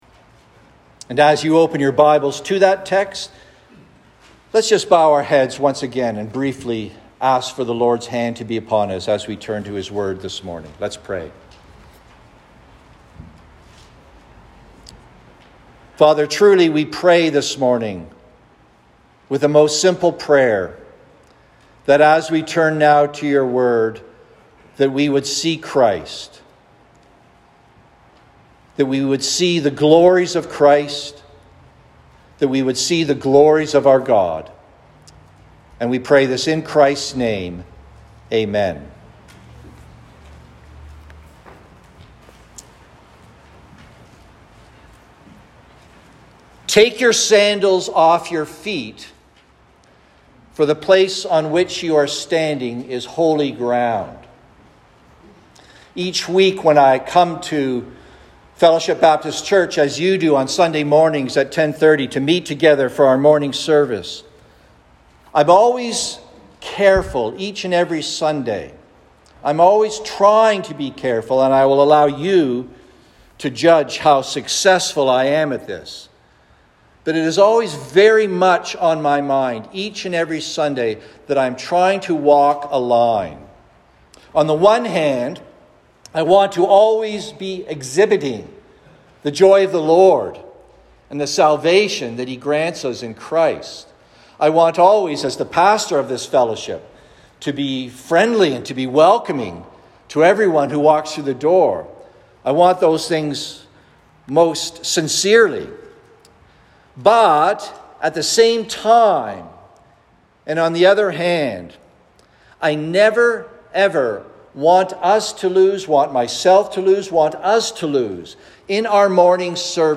Sermons | Cranbrook Fellowship Baptist
Alternatively, the .mp3 audio recording above is of better quality.